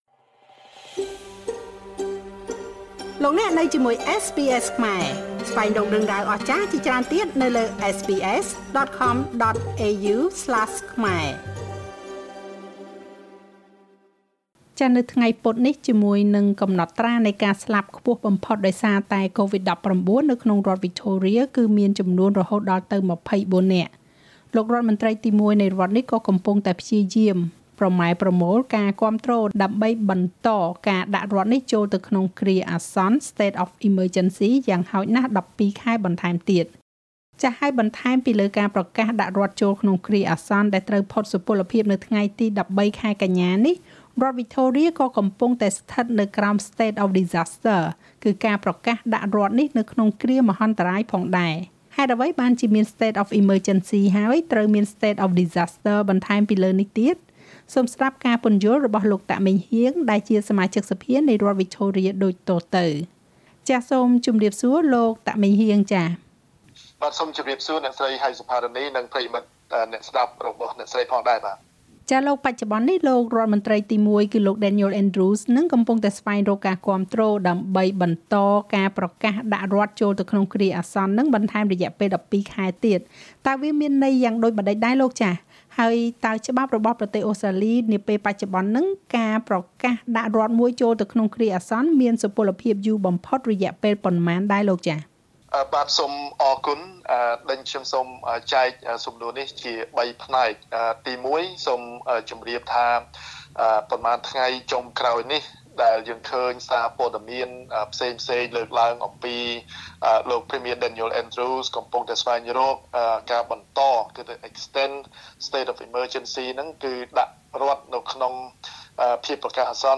ហេតុអ្វីបានជាមានState of Emergecy ហើយ ត្រូវមាន State of Disaster បន្ថែមពីលើនេះទៀត? សូមស្តាប់ការពន្យល់របស់លោក តាក ម៉េងហ៊ាង សមាជិកសភានៃរដ្ឋវិចថូរៀដូចតទៅ។